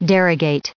1178_derogate.ogg